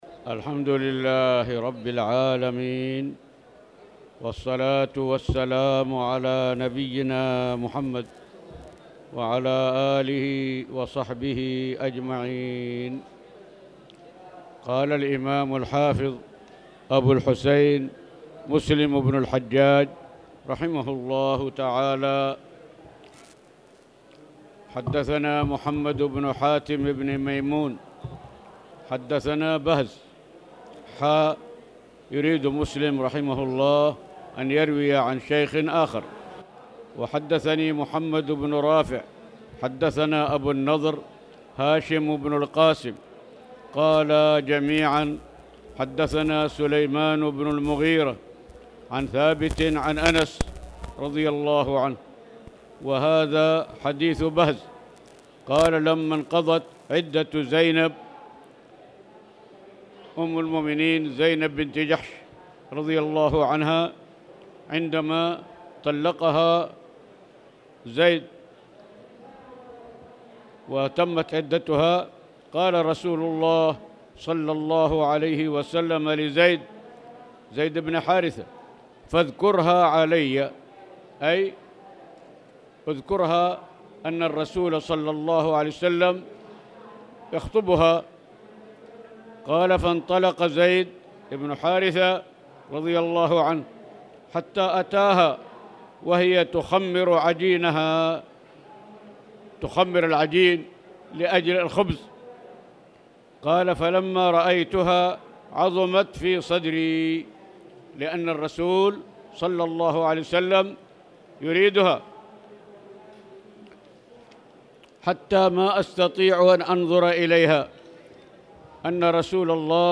تاريخ النشر ٢٨ صفر ١٤٣٨ هـ المكان: المسجد الحرام الشيخ